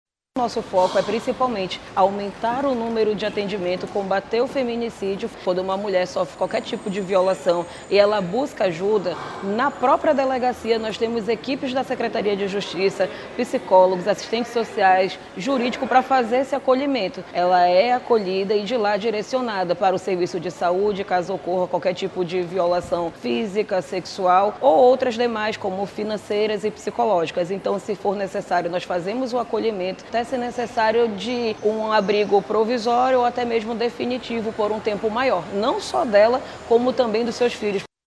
A secretária de Estado de Justiça, Direitos Humanos e Cidadania, Jussara Pedroso, explica como funciona a rede de proteção, que reúne equipes multiprofissionais.